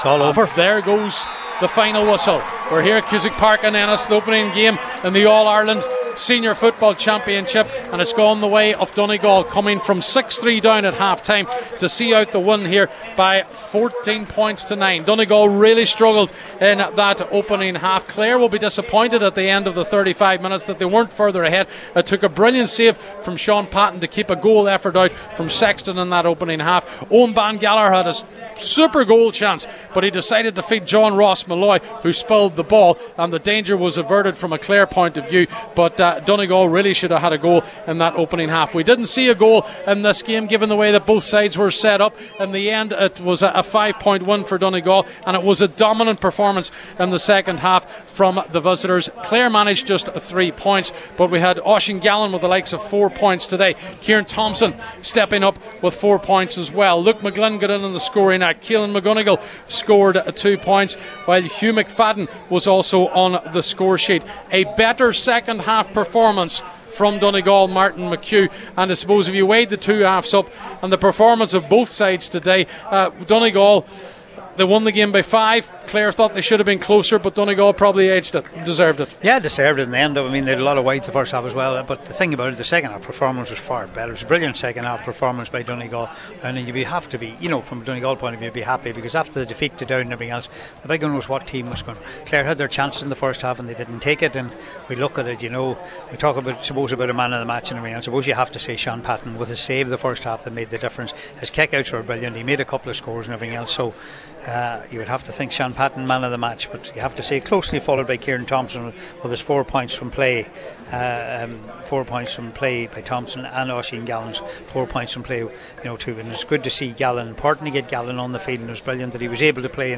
the full time report from Cusack Park…